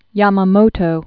(yämə-mōtō, -mä-), Isoroku 1884-1943.